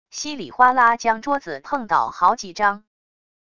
稀里哗啦将桌子碰倒好几张wav音频